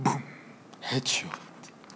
Explo_3.wav